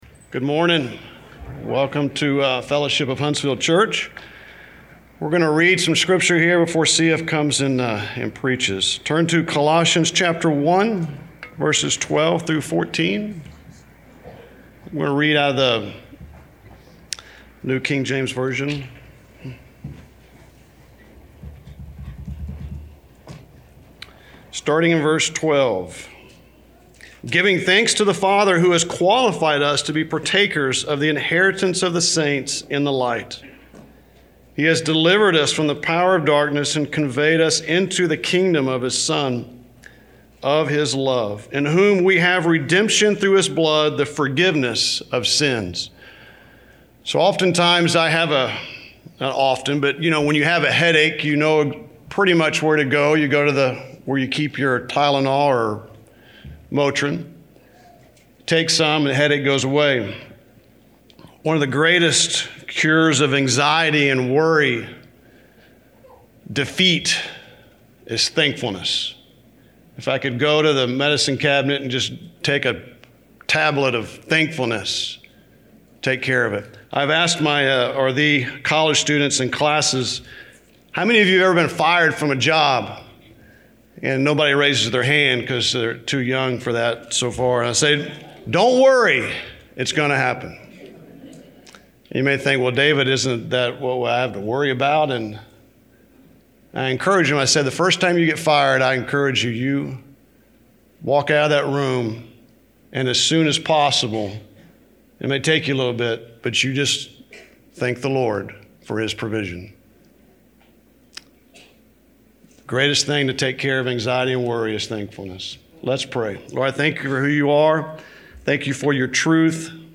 Fellowship of Huntsville Church Sermon Archive